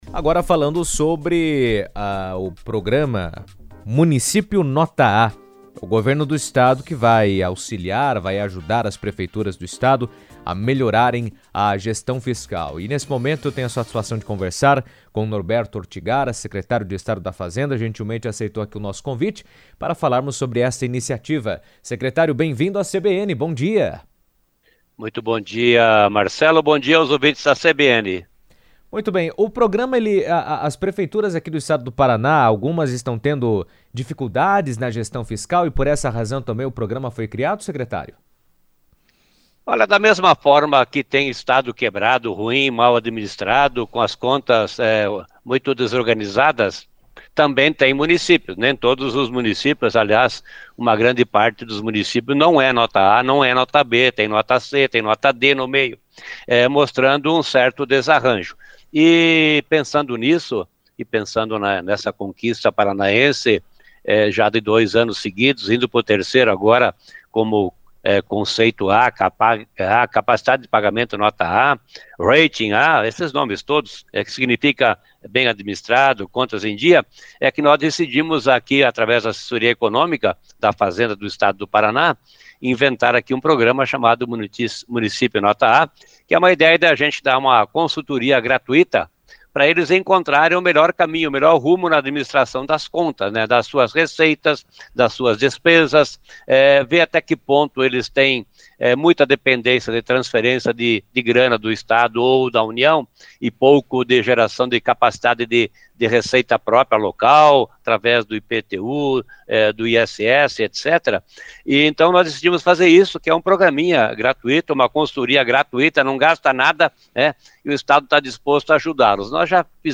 Em entrevista à CBN, o secretário Norberto Ortigara afirmou que o objetivo é compartilhar a expertise do Estado para melhorar a gestão pública, ampliando a capacidade de investimento e a sustentabilidade financeira das cidades.